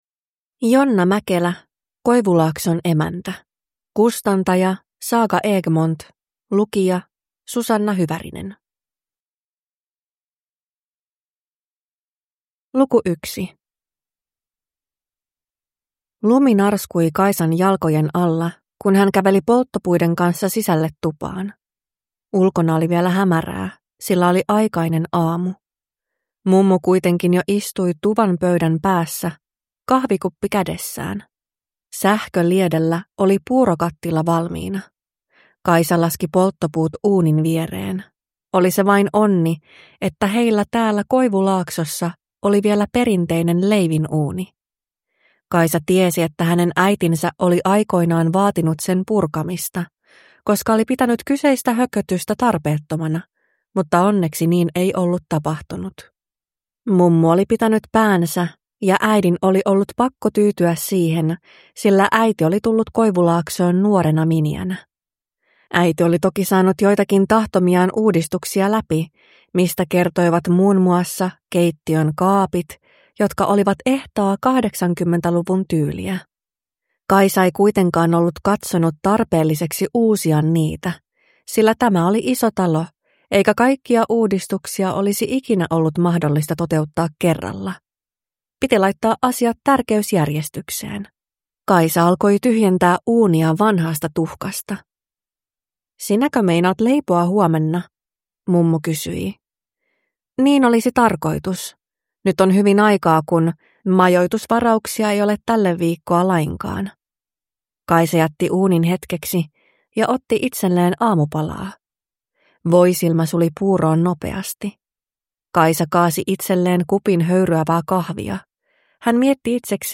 Koivulaakson emäntä – Ljudbok